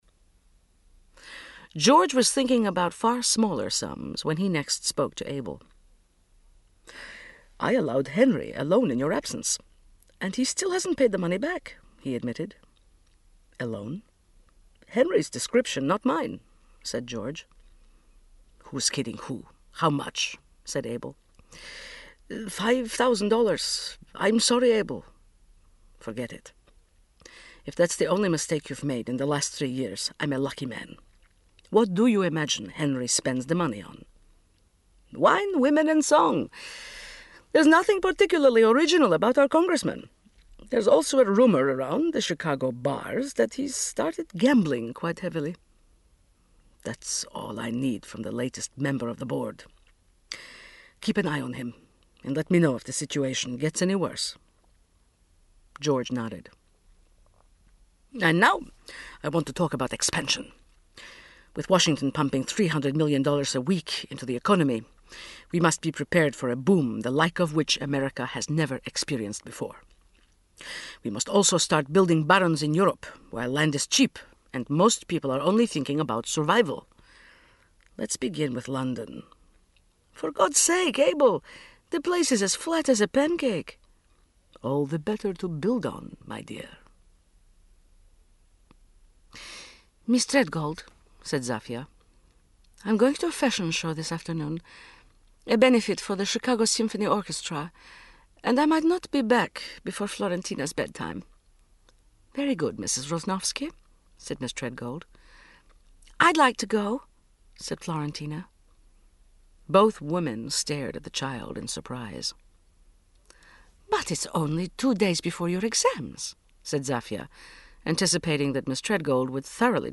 37serv Audiobooks/Jeffrey Archer - Prodigal Daughter (1982) (96)